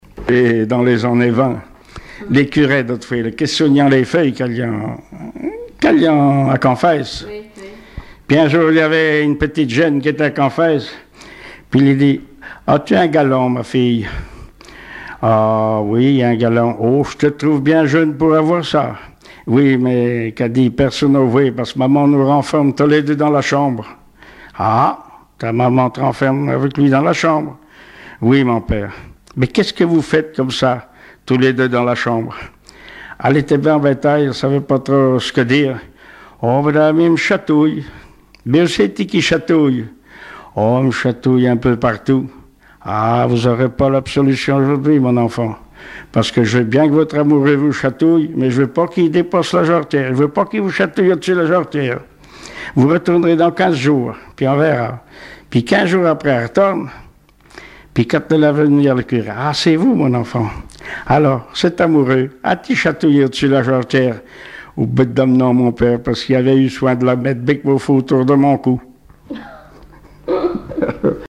Genre sketch
Témoignages et chansons traditionnelles et populaires
Catégorie Récit